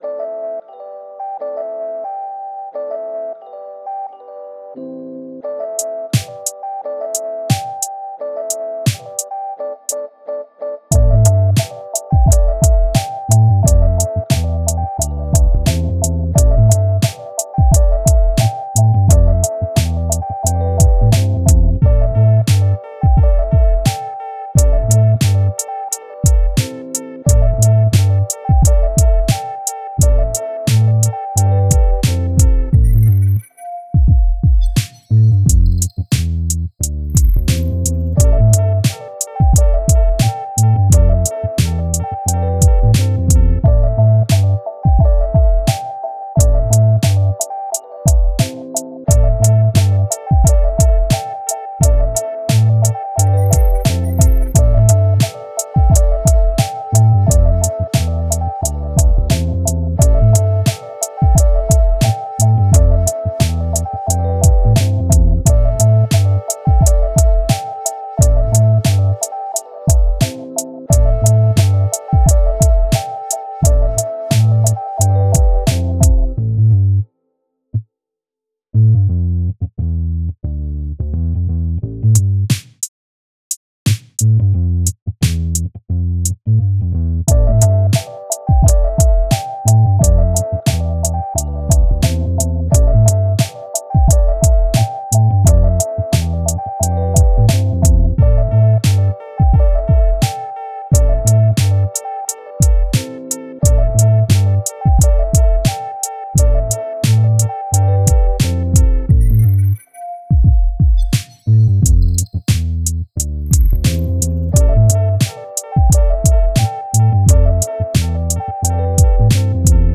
BPM88 Key Am 構成 イントロ8 バース16 フック8 間奏4 バース16 フック16 アウトロ4